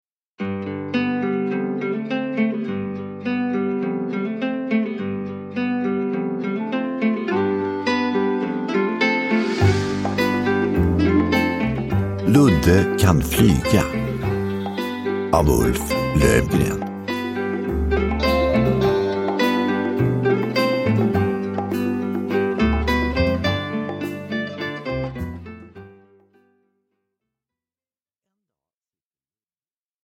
Ludde kan flyga – Ljudbok – Laddas ner